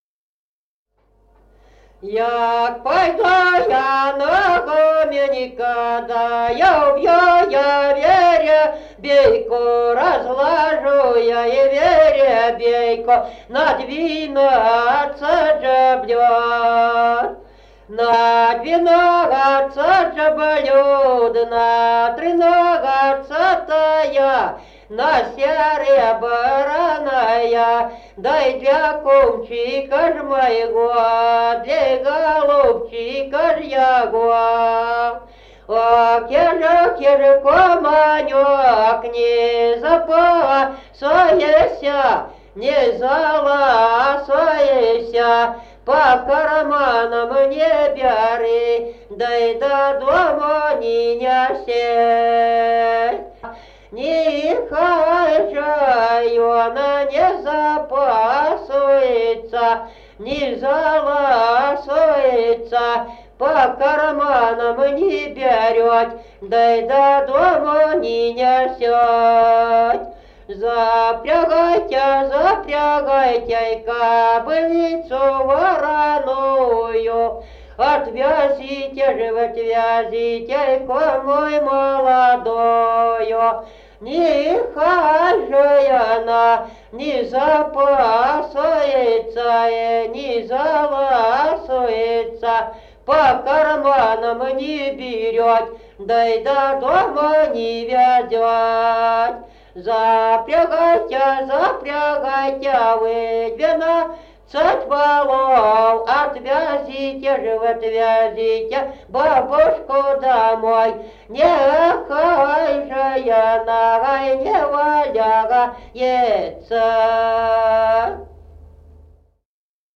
Народные песни Стародубского района «Як пойду я на гуменько», крестинная.
1953 г., д. Камень.